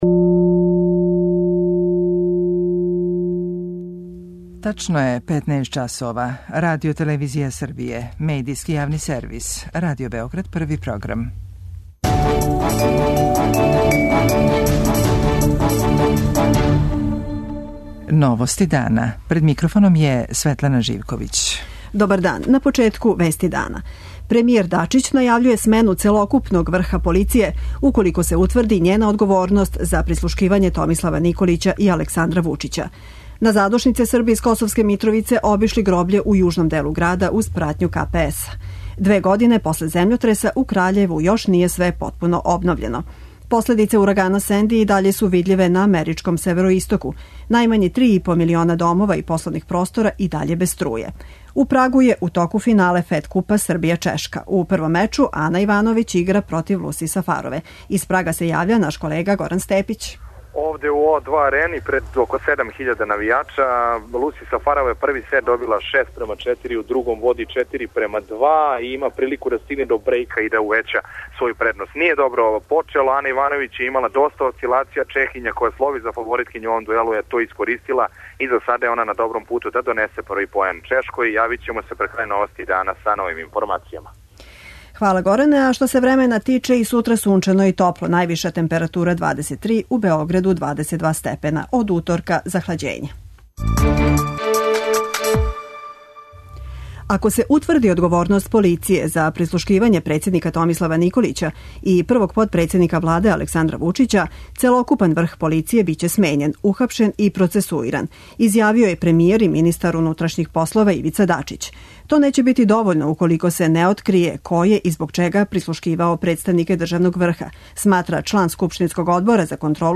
Данас се навршава сто дана рада Владе Србије. Тим поводом, за Новости дана говоре аналитичари и стручњаци.
преузми : 15.38 MB Новости дана Autor: Радио Београд 1 “Новости дана”, централна информативна емисија Првог програма Радио Београда емитује се од јесени 1958. године.